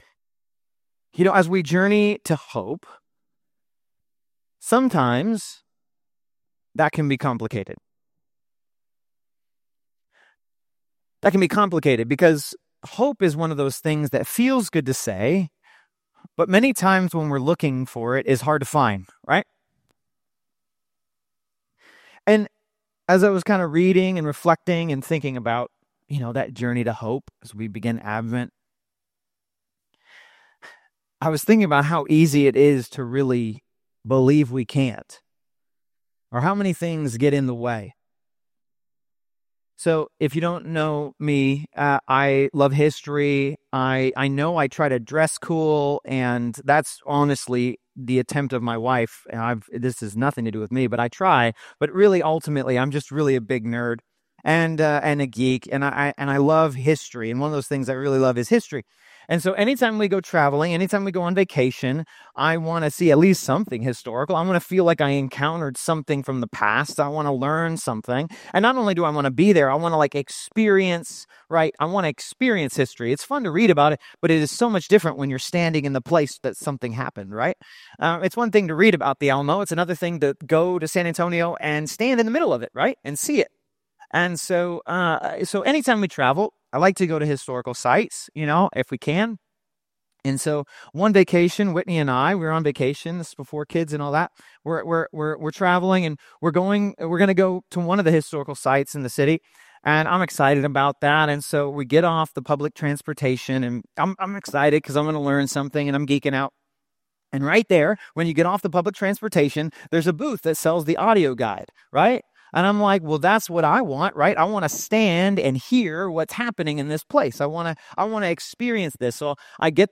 2024 Abound in Love Preacher